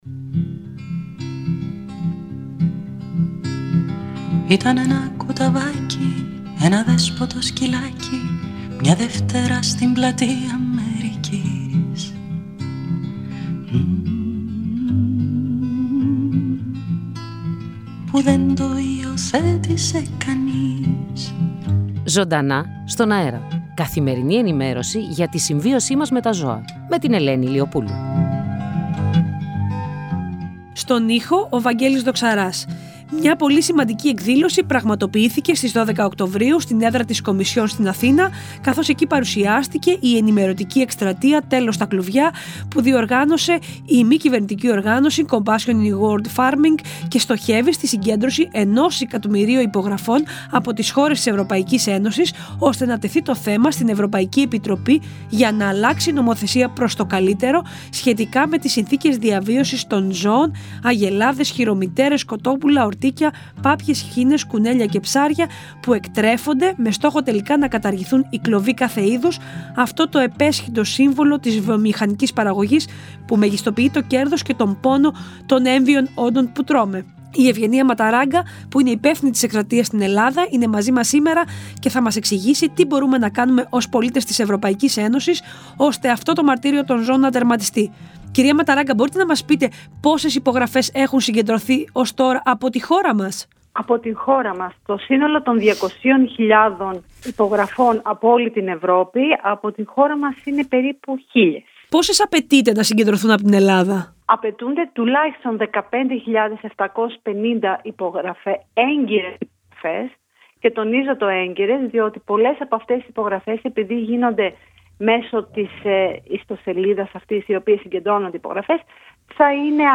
μιλώντας στον ραδιοφωνικό σταθμό Στο Κόκκινο 105,5 FM